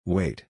/weɪt/